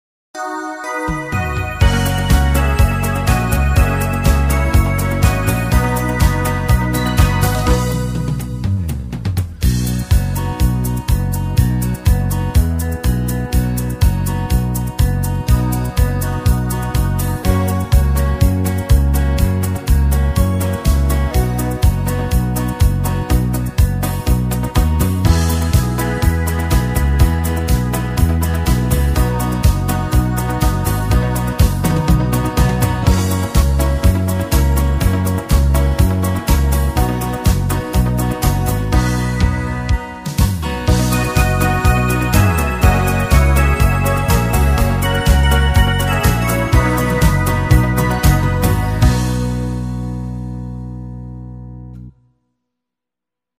Discofox